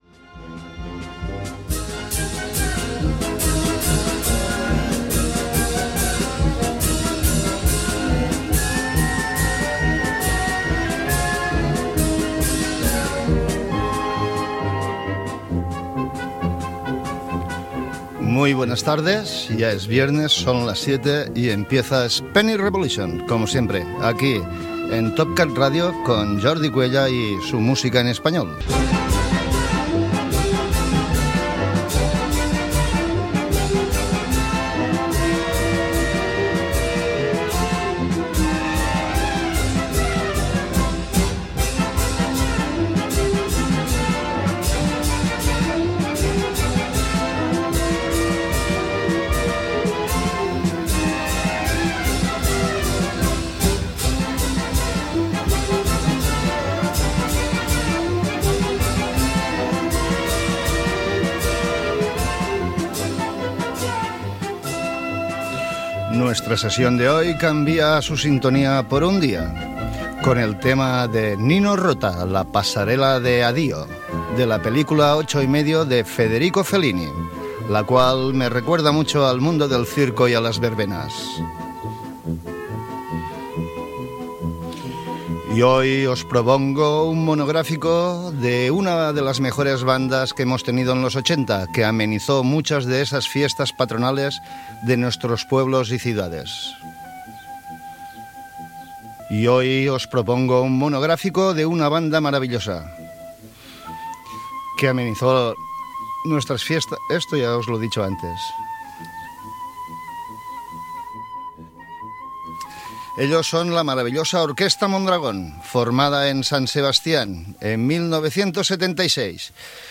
Música inicial i presentació. Espai monogràfic dedicat a la Orquesta Mondragón
Musical